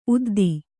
♪ uddi